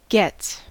Ääntäminen
Ääntäminen US Tuntematon aksentti: IPA : /ˈɡɛts/ Haettu sana löytyi näillä lähdekielillä: englanti Gets on sanan get monikko.